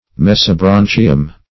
Search Result for " mesobronchium" : The Collaborative International Dictionary of English v.0.48: Mesobronchium \Mes`o*bron"chi*um\, n.; pl.